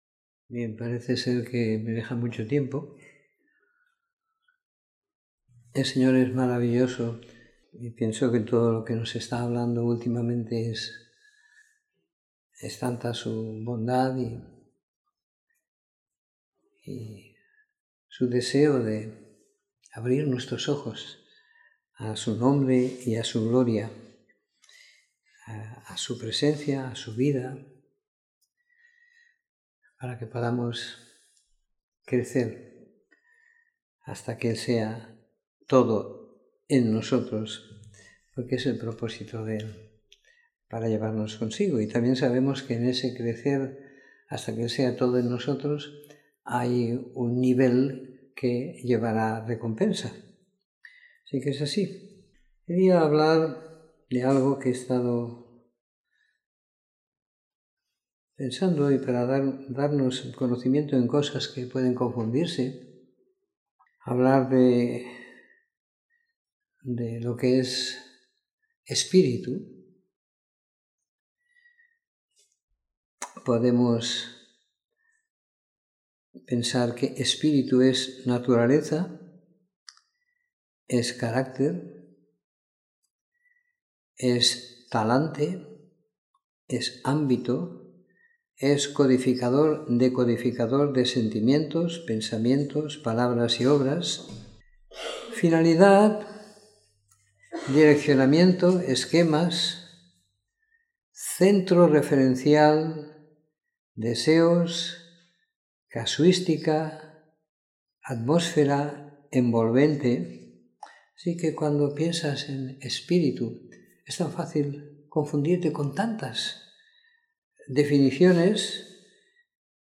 Domingo por la Tarde . 11 de Febrero de 2018